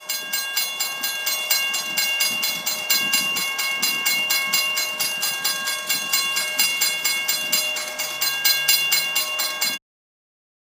Train crossing
bell clang crossing ding ring train sound effect free sound royalty free Sound Effects